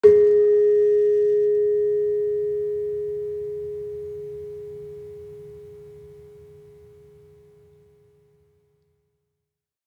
Gender-1-G#3-f.wav